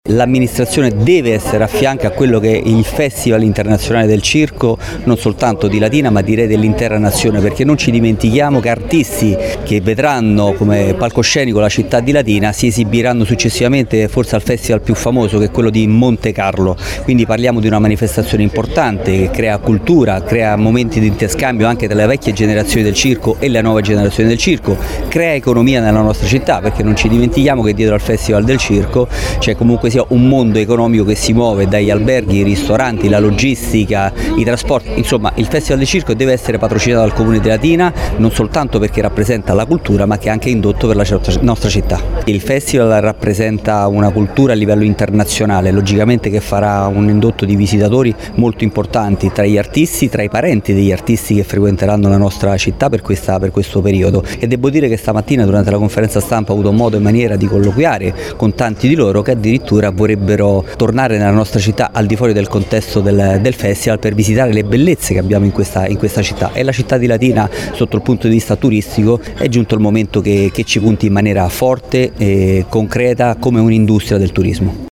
Latina ha ufficialmente acceso i riflettori sulla 26ª edizione del Festival Internazionale del Circo d’Italia, con la conferenza stampa di presentazione che si è svolta nella suggestiva cornice della Cittadella del Circo.
A rappresentare l’amministrazione c’è anche l’Assessore al Turismo Gianluca Di Cocco: «Il festival è cresciuto, è diventato un riferimento internazionale e un’occasione per Latina di essere protagonista… :